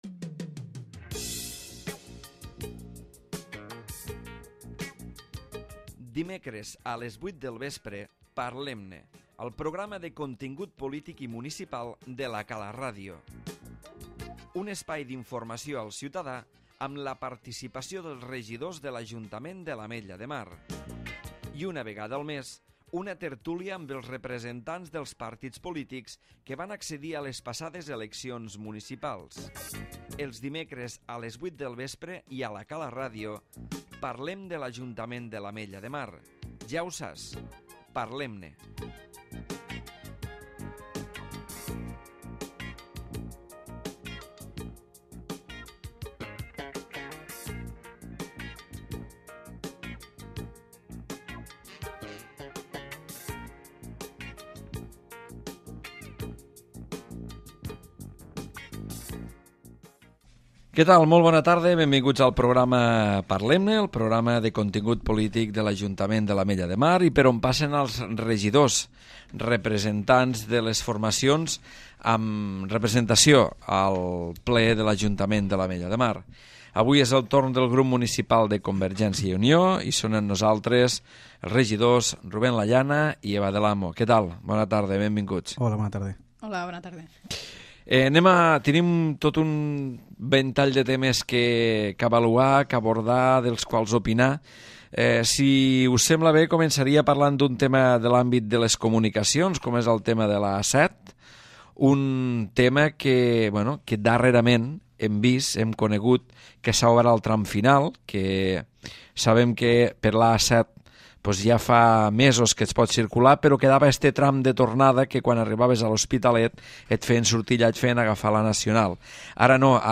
El regidor Ruben Lallana i la regidora Eva del Amo, han parlat al Parlem-ne de CiU, dels temes més destacats de l'actualitat comercial, entre d'altres, del rebuig al centre experimental d'energia eòlica, del desplegament de la fibra òptica i el gas natural, del suport a la continuïtat de la A-7 i de la residencia per a la gent gran entre d'altres.